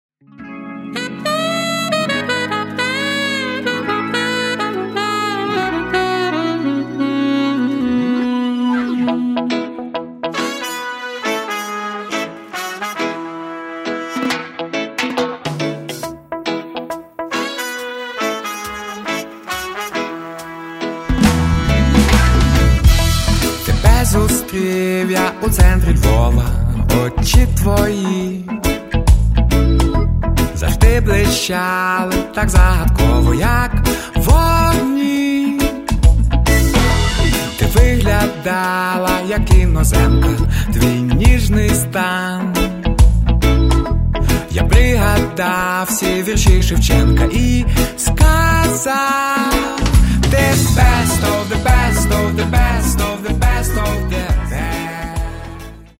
Catalogue -> Rock & Alternative -> Reggae